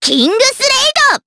Miruru-Vox_Kingsraid_jp.wav